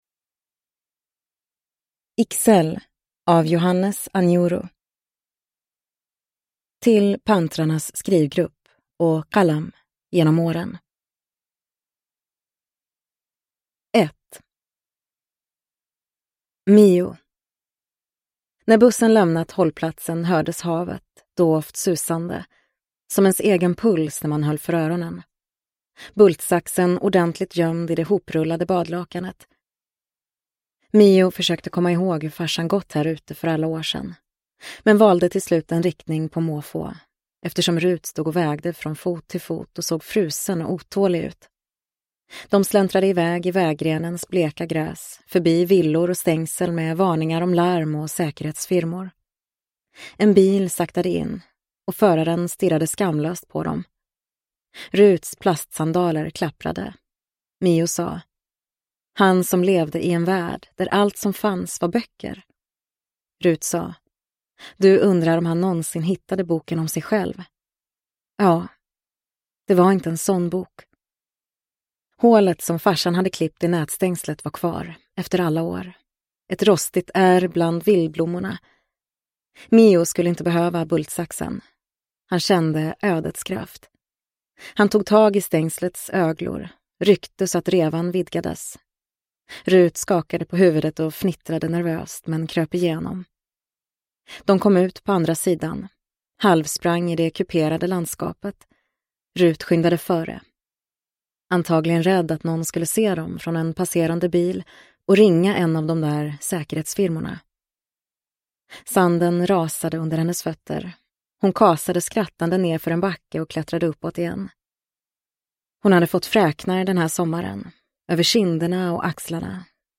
Ixelles – Ljudbok – Laddas ner